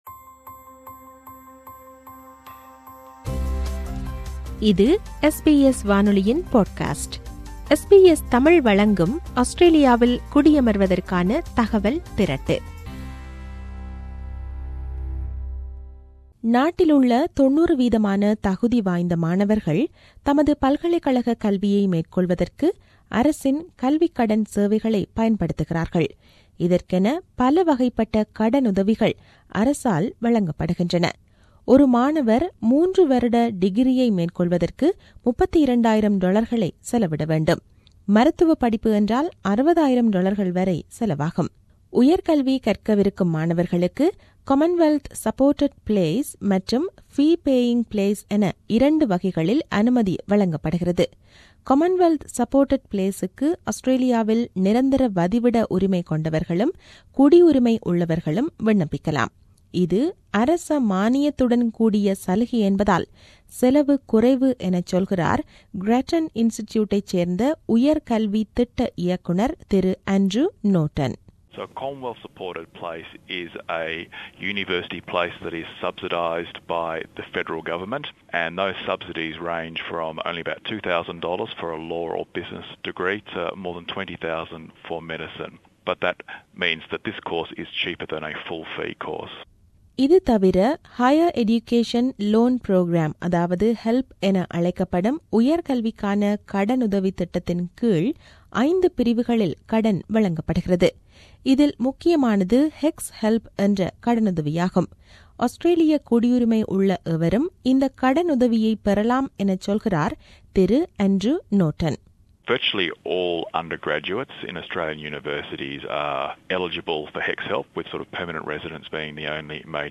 விவரணத்தை தமிழில் தருகிறார்